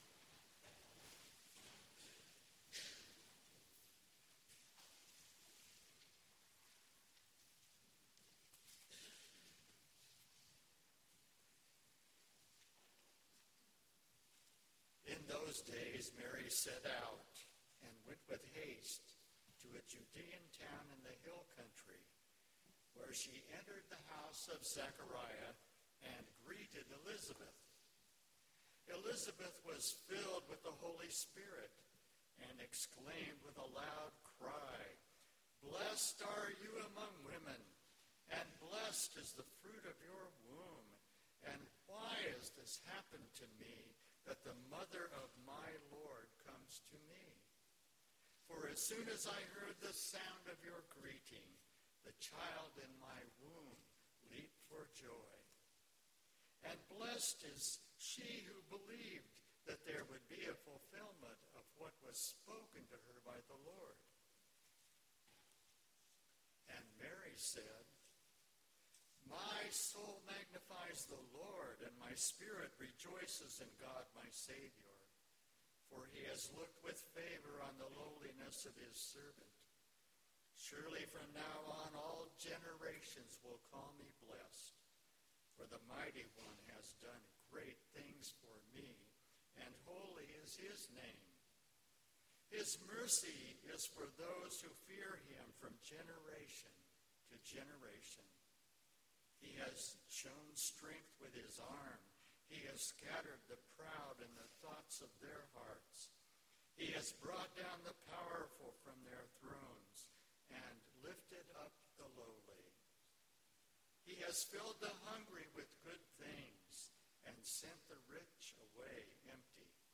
Lessons and Carols Part 2 – St Benedict's Episcopal Church
Lessons and Carols Part 2